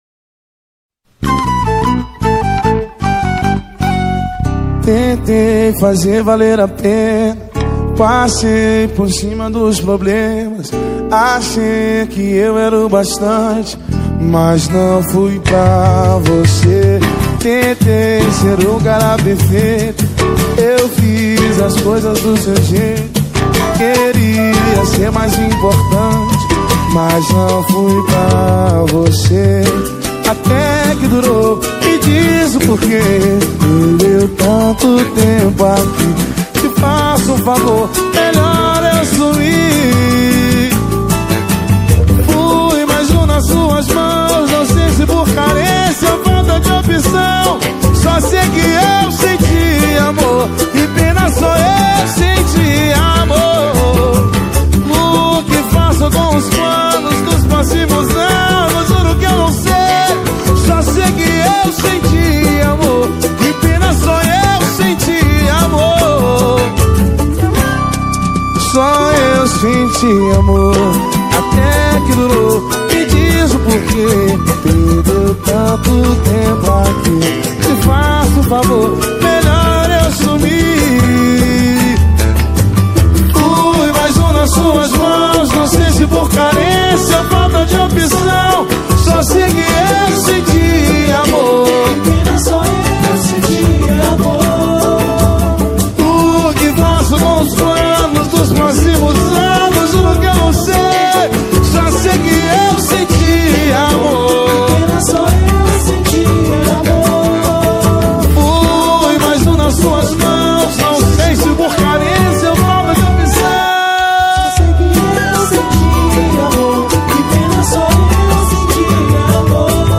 2025-03-22 01:28:24 Gênero: Pagode Views